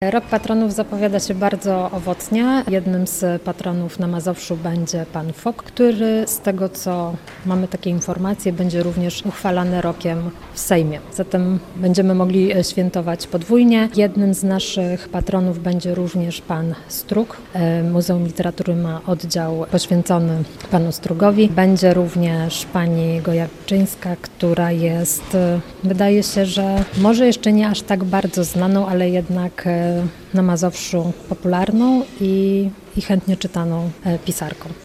Mieszkańcy województwa mogą uczcić pamięć osób, dzięki którym region jest dziś promowany – mówi Katarzyna Bornowska, przewodnicząca sejmikowej Komisji Kultury i Dziedzictwa Narodowego.